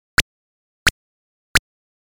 Чирик!
Пожалуй, самый банальный биперный эффект, который можно слышать в тысячах игр для ZX Spectrum, условно назовём его «щебет» (быстро падающая высота тона, похожая на чириканье птицы), в коде выглядит так:
Общая высота тона задаётся скоростью выполнения этого кода, и в первую итерацию составляет примерно 2600 Гц, а в последнюю — примерно 58300 Гц.
zxsfx_tweet.mp3